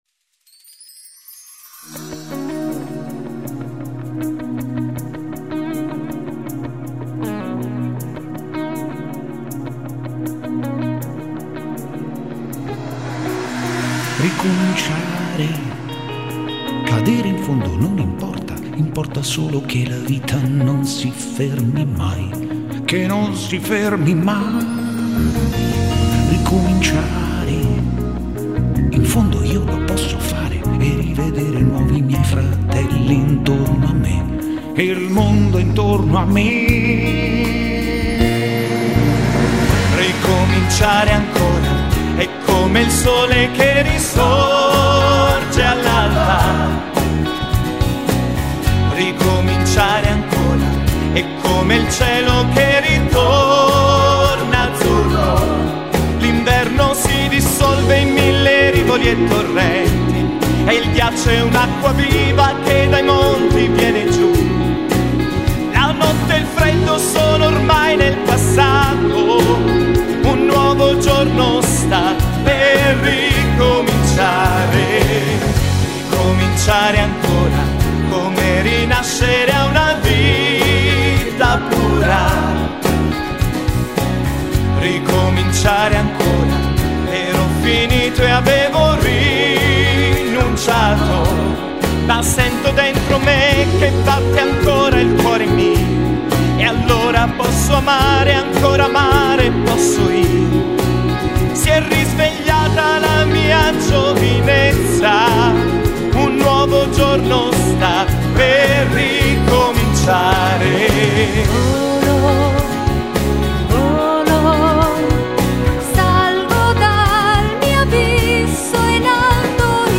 concerto che spesso faccio dal vivo